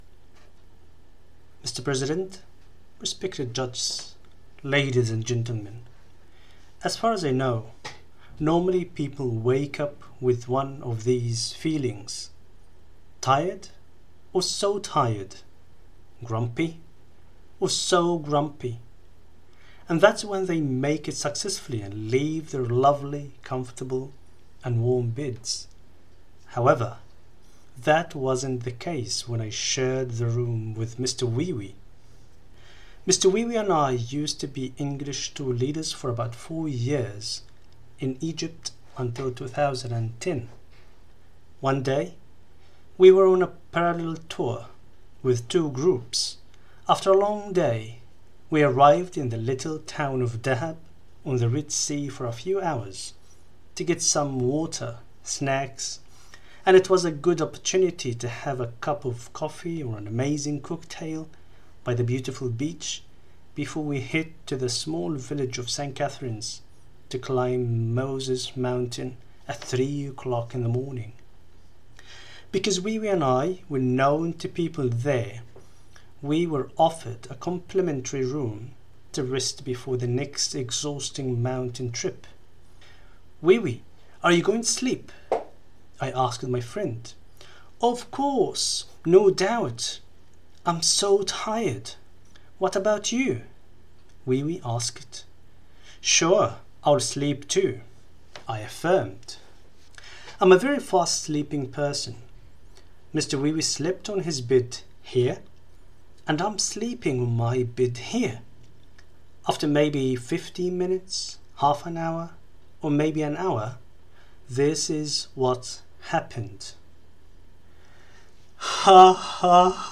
Third speech - Tall Tale